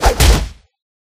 Blow9.ogg